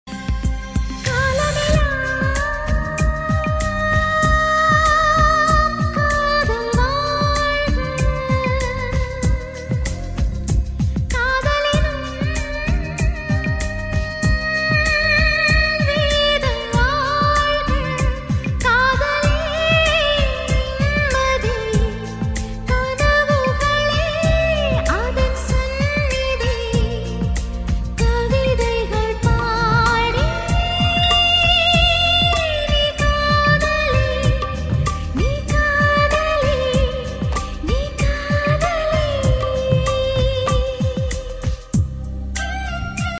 best flute ringtone download | love song ringtone
melody ringtone romantic ringtone